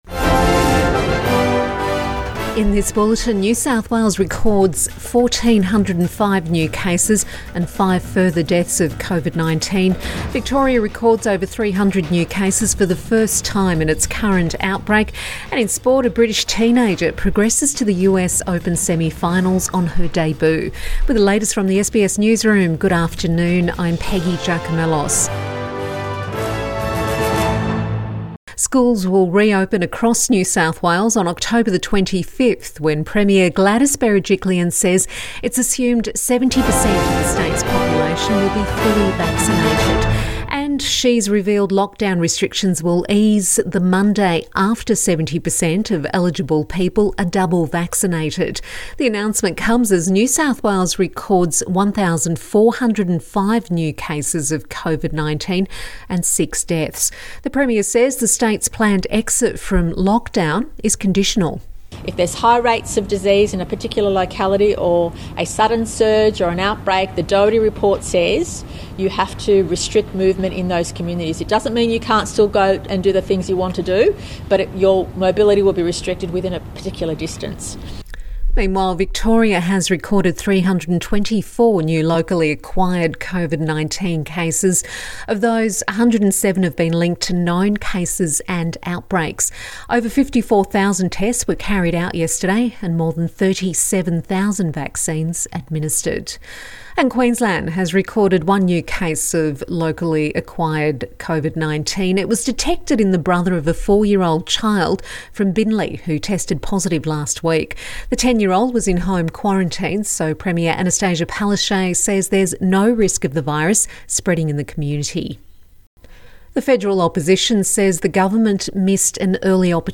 Midday bulletin 9 September 2021